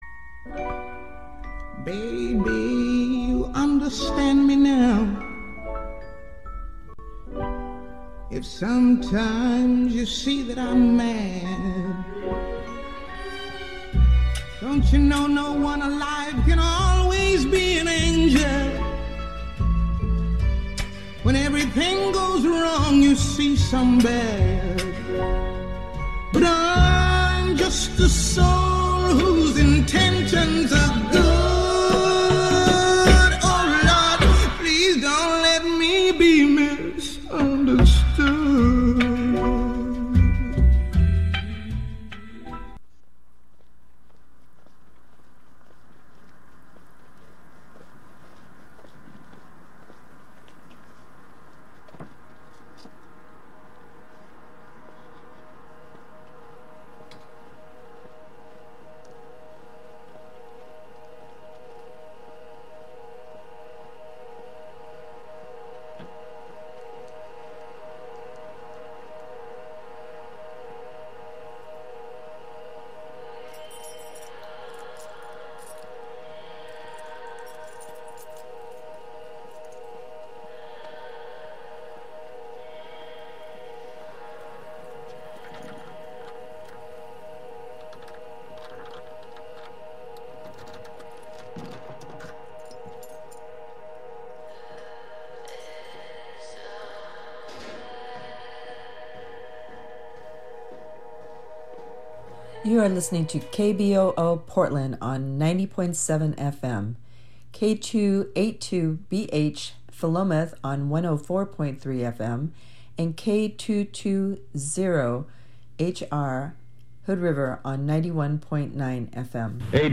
Every 1st Monday from 7:00 pm to 8:00 pm Let’s Talk About Race (LTAR), a new intergenerational, roundtable discussion of independent national journalists featuring rigorous conversations and analysis of news coverage and the role race plays in politics, government, economy, education, and health.